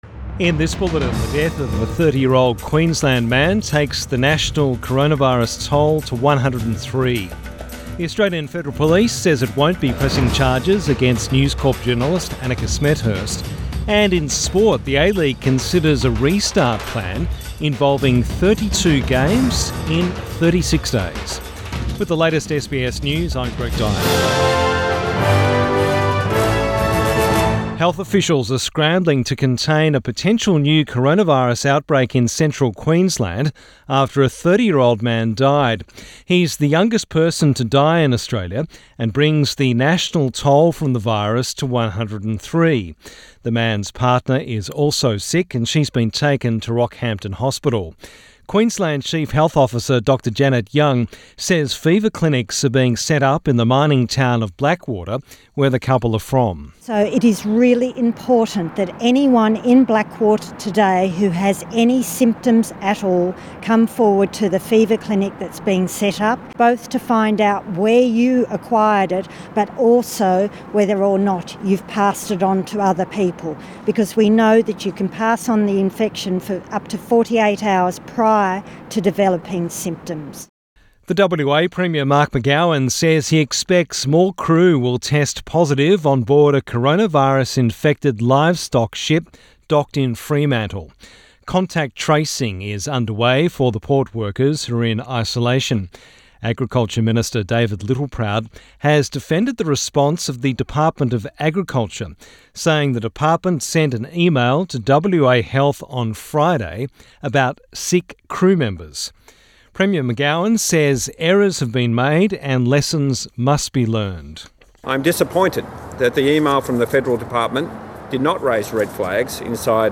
PM bulletin 27 May 2020